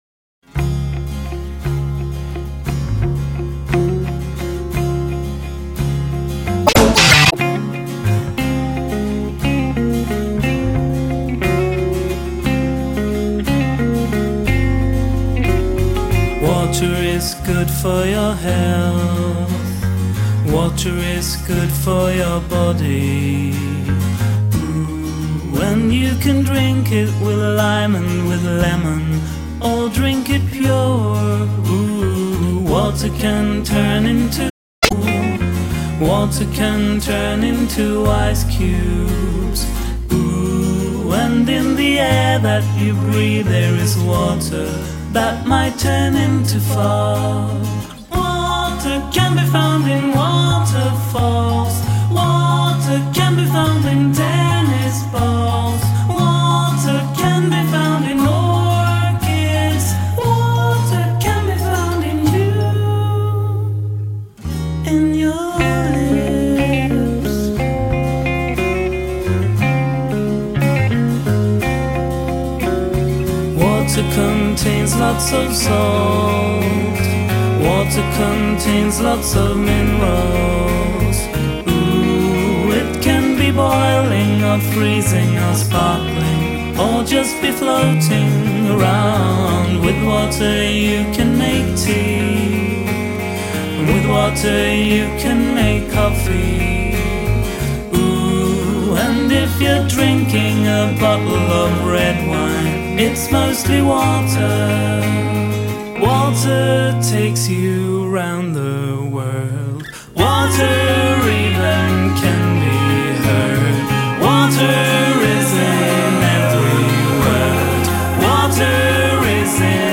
I have a weakness for Swedish pop
exceptionally sweet